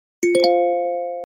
notification.ogg